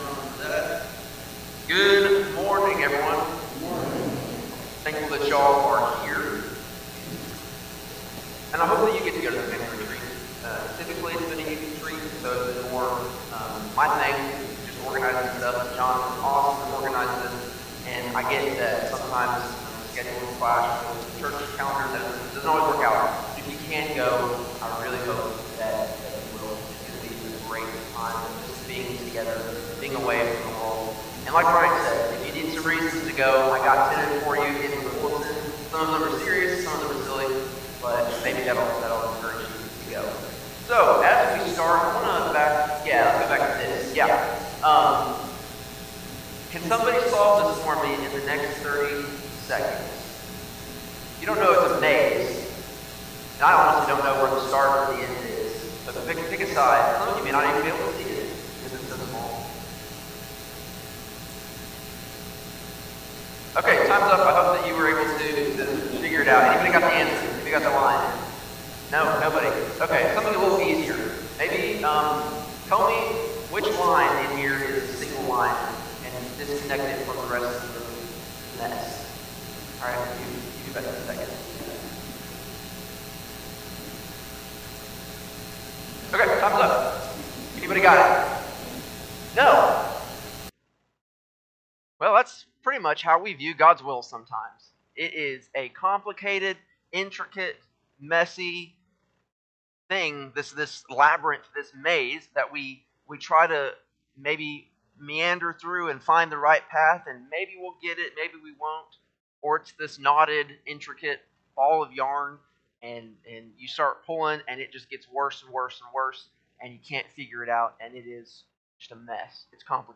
Sunday-AM-Sermon-8-17-25.mp3